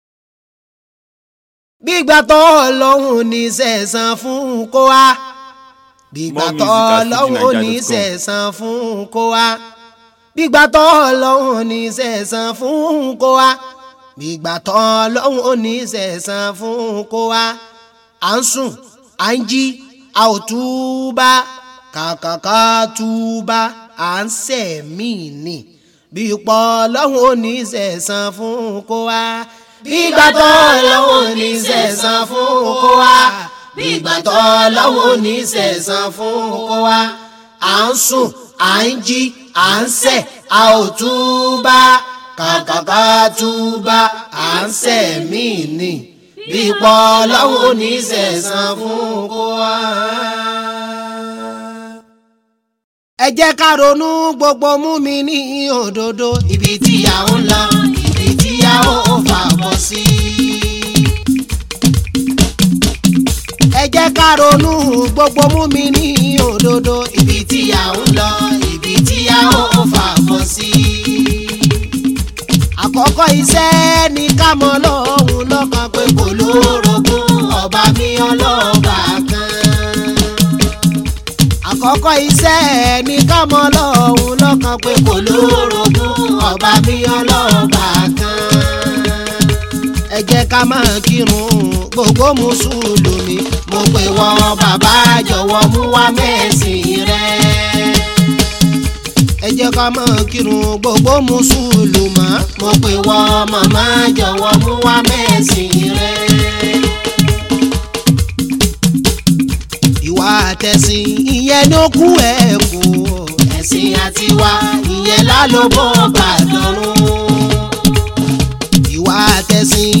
Latest Yoruba Islamic Music below